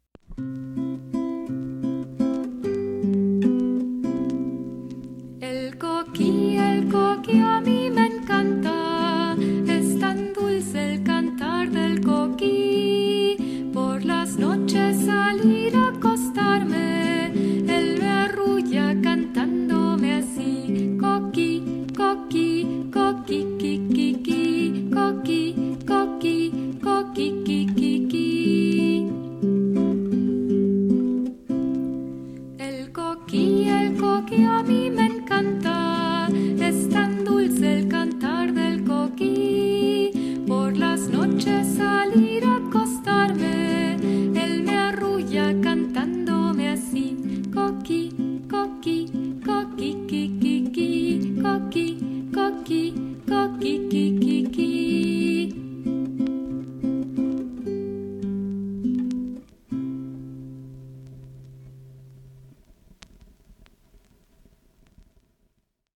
spanisches Kinderlied